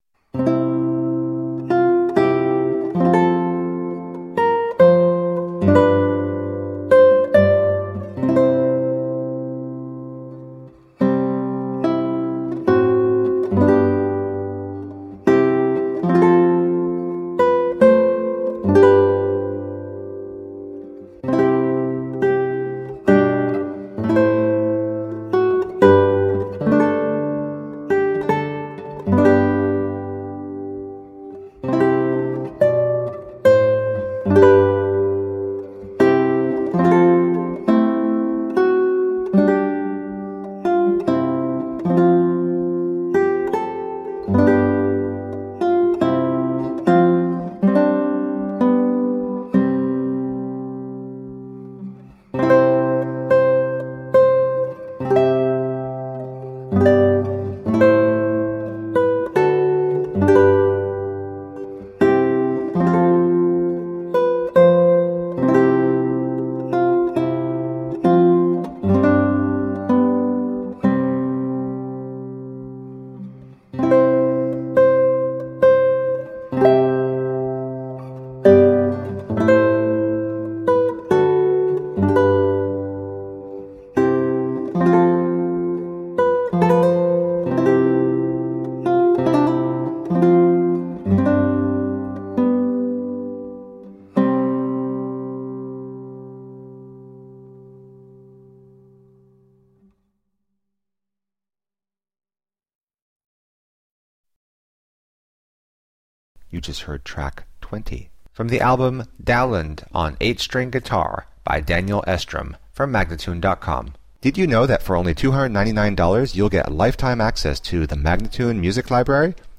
Colorful classical guitar.
played on 8-string guitar